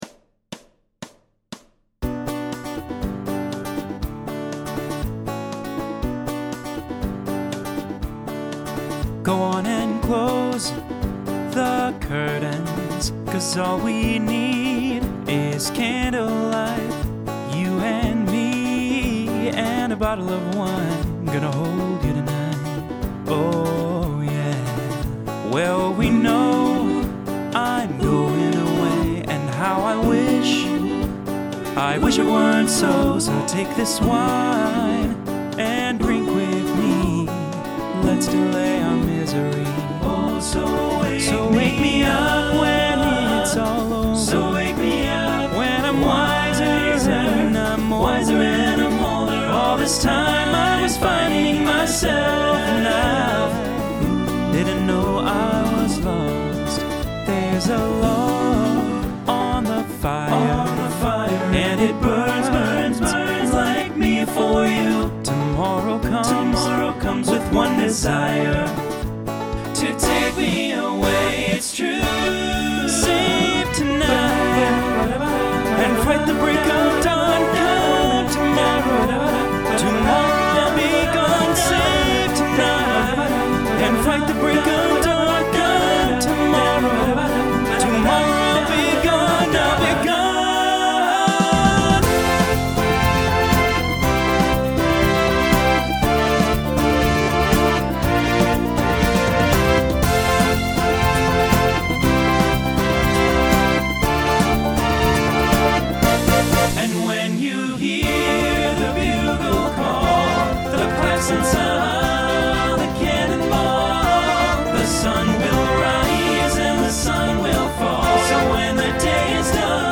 Broadway/Film , Folk
Show Function Solo Feature , Transition Voicing TTB